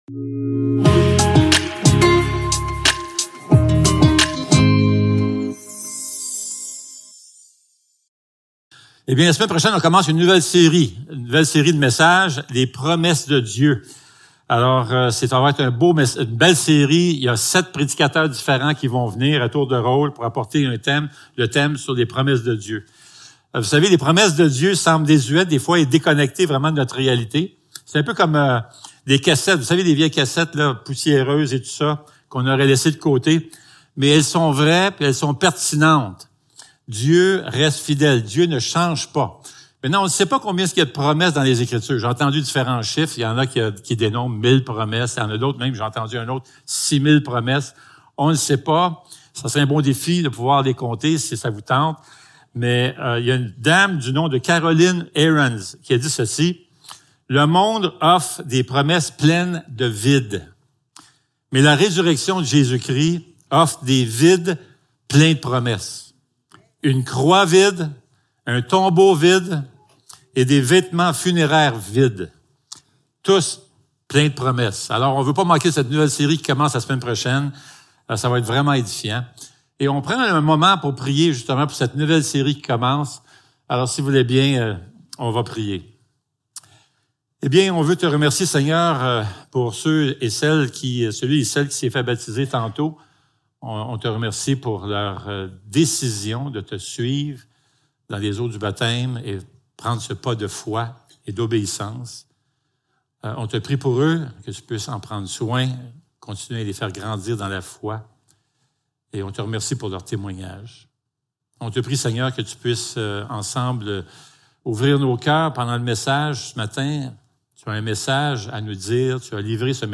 3 Jean Service Type: Célébration dimanche matin Description